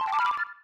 transmogrify.ogg